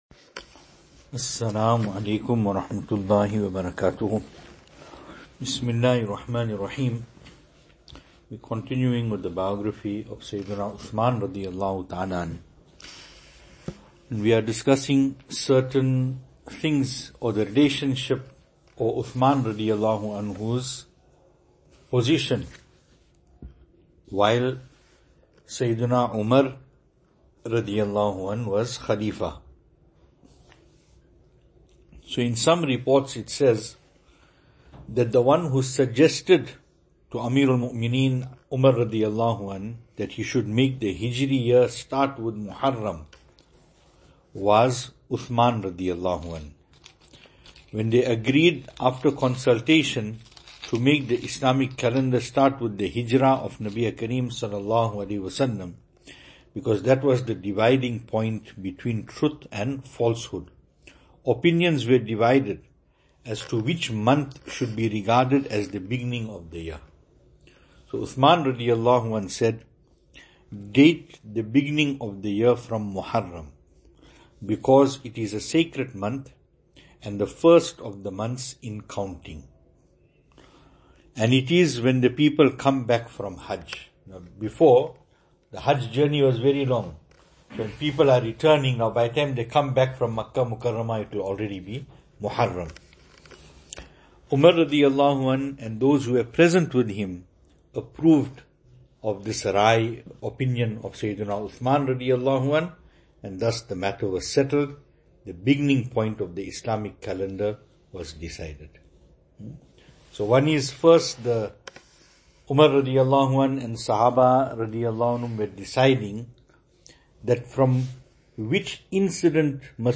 2025-09-18 Biography of Hadhrat Uthmaan RadiAllahu anhu Venue: Albert Falls , Madressa Isha'atul Haq Series: Hz Uthmaan RA Service Type: Majlis Topics: Hz Uthmaan RA « It is Fardh to love Nabi e Kareem Sallallahu Alaihi Wasallam.